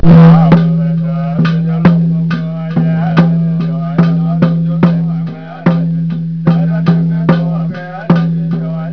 Buddhist monks chanting in a home